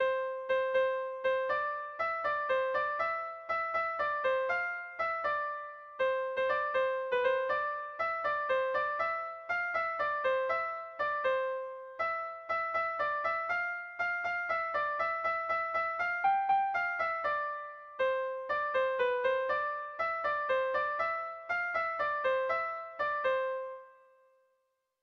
Irrizkoa
A1A2BA3